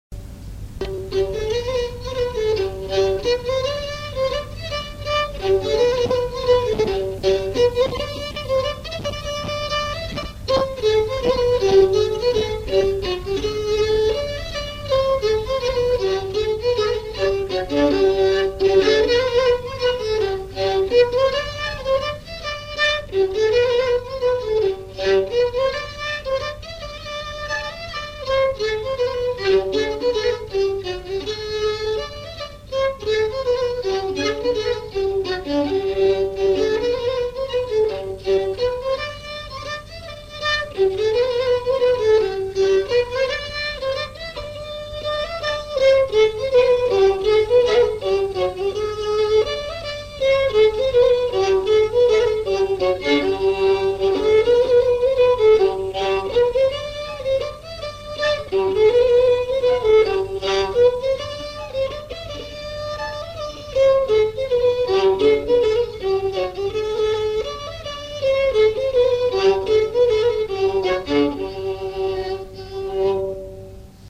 danse : polka
enregistrements du Répertoire du violoneux
Pièce musicale inédite